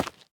Minecraft Version Minecraft Version snapshot Latest Release | Latest Snapshot snapshot / assets / minecraft / sounds / block / tuff / step6.ogg Compare With Compare With Latest Release | Latest Snapshot
step6.ogg